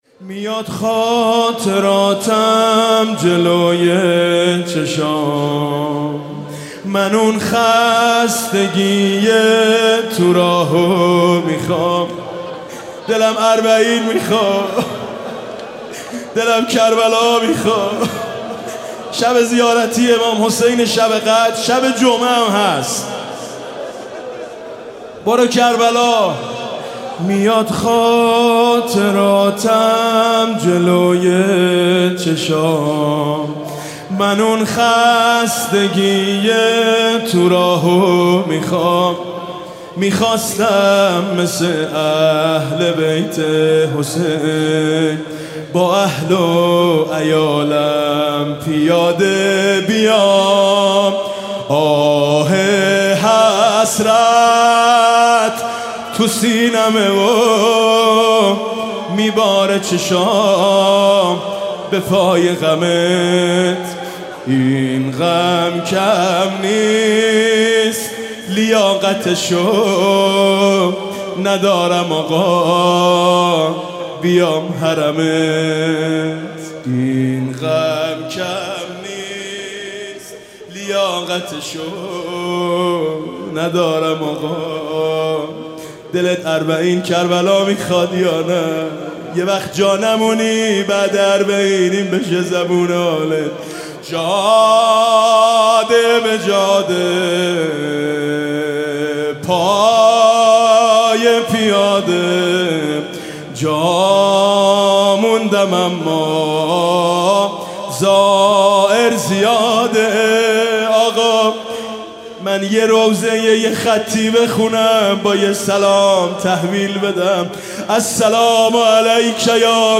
رمضان
زمزمه